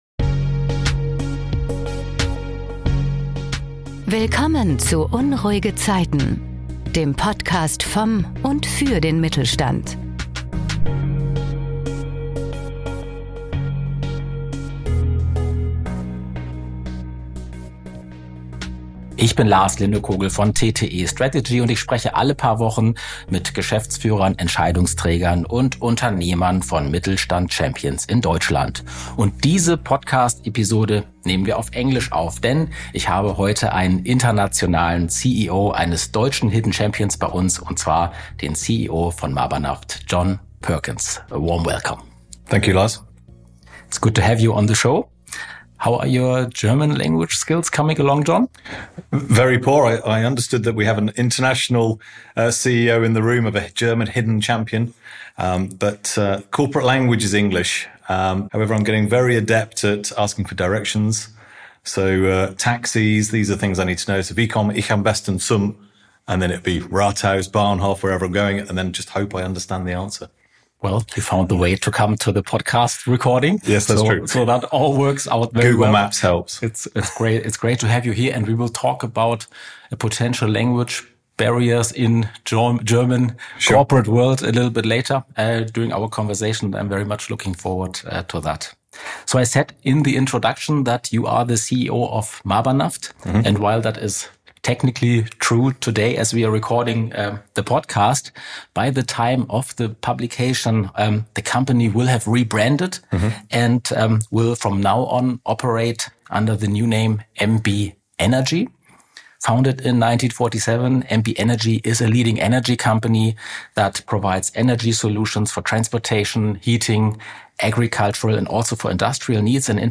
Für mehr interessante Geschichten und Interviews aus dem Mittelstand: Jetzt den Podcast abonnieren.